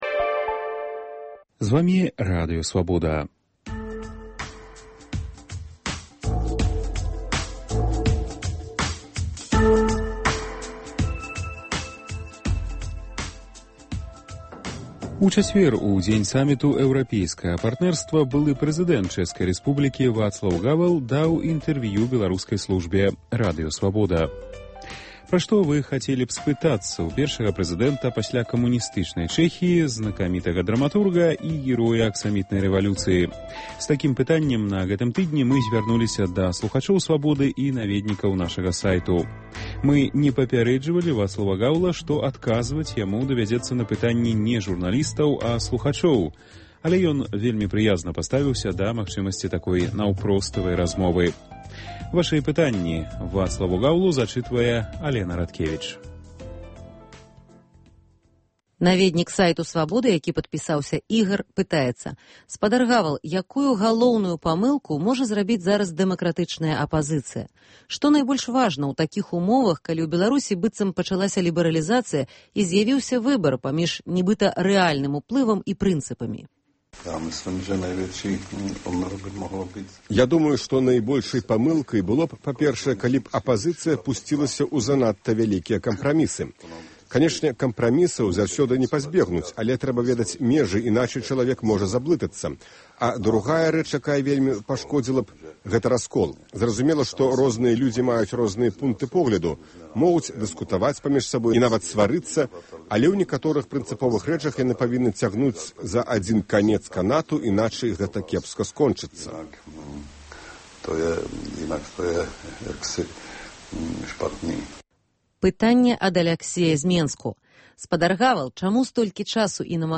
Першы прэзыдэнт Чэхіі Вацлаў Гавал адказвае на пытаньні наведнікаў нашага сайту, слухачоў і журналістаў Радыё Свабода.